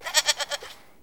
Sheep
Sheep.wav